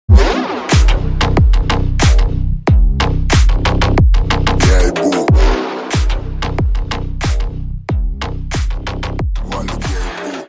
• Качество: 128, Stereo
мощные басы
Bass House
G-House